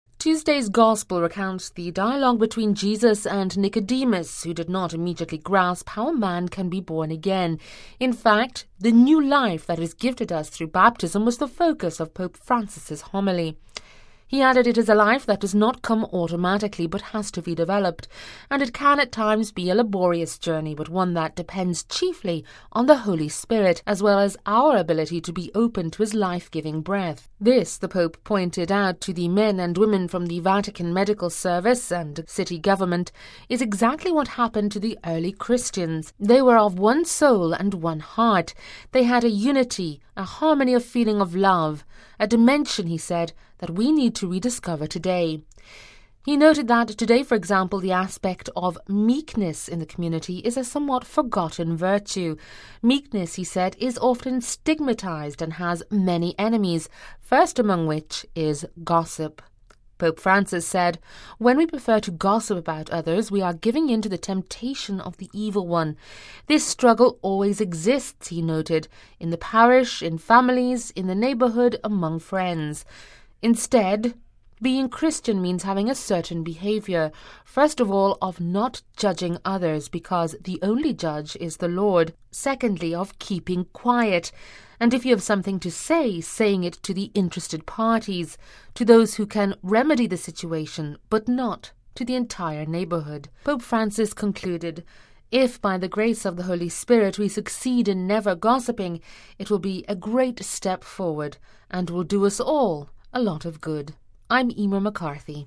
(Vatican Radio) May the Holy Spirit bring peace to Christian communities and teach its members to be meek, refusing to speak ill of others. With this hope, Pope Francis concluded his homily at Mass Tuesday morning with staff from the Vatican medical services and office staff of the Vatican City Government.